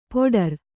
♪ poḍar